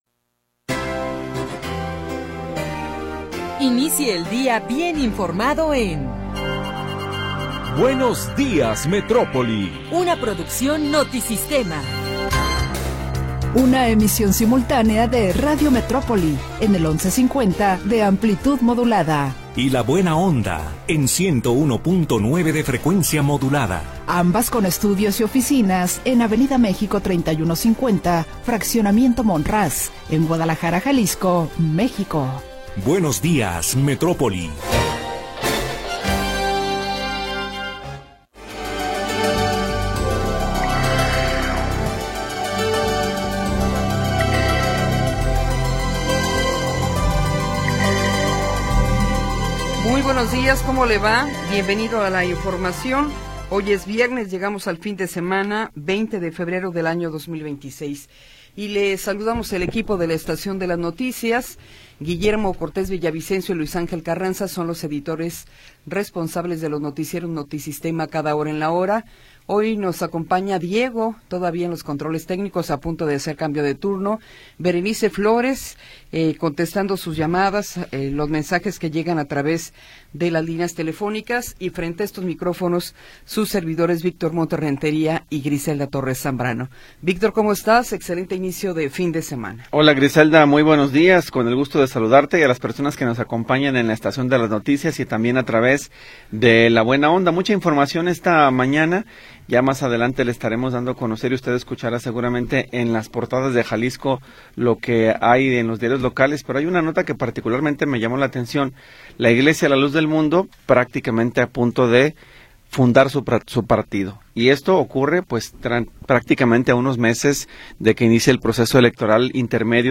Primera hora del programa transmitido el 20 de Febrero de 2026.